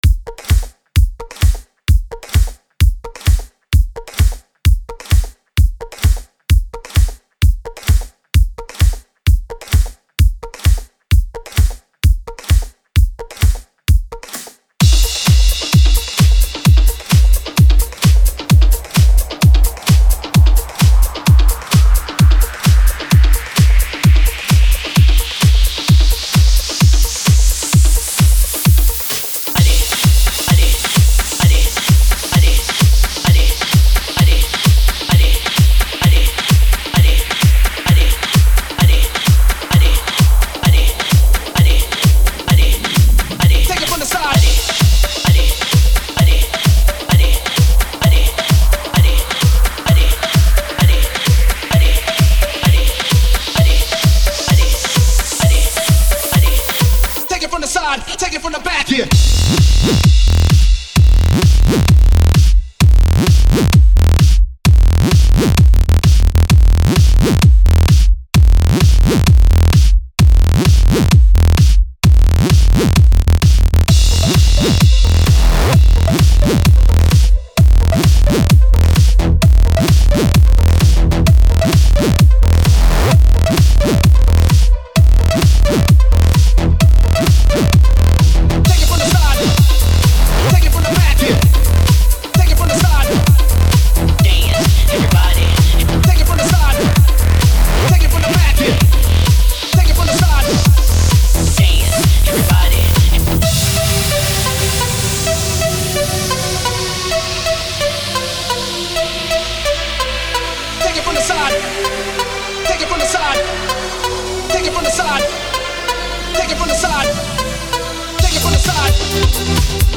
Категория: Club Music - Клубная музыка